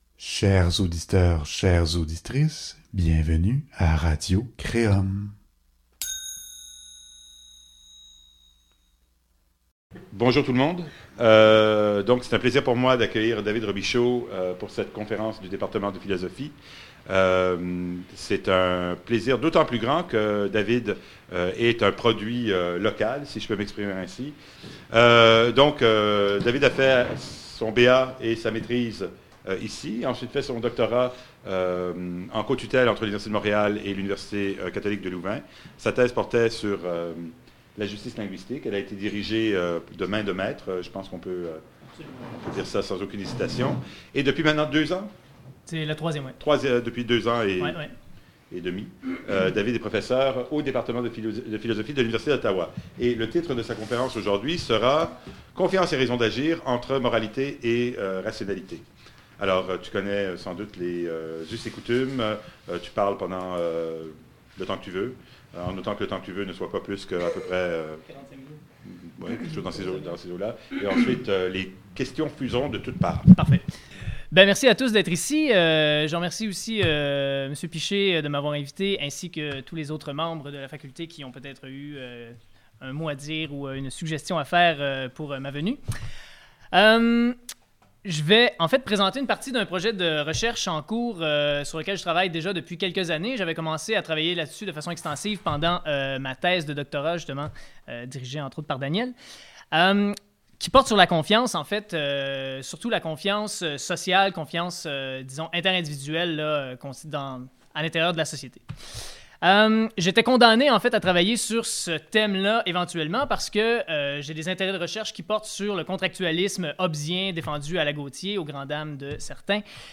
Conférences, colloques et ateliers